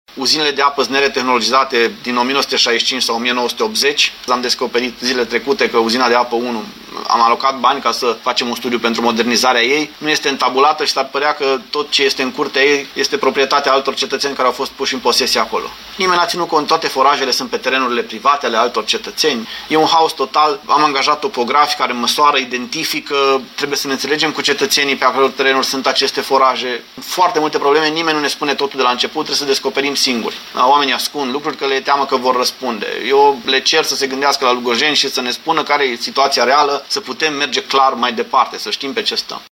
Primarul Claudiu Buciu spune că această situație este posibilă pentru că angajații administrației locale nu și-au făcut treaba la momentul potrivit.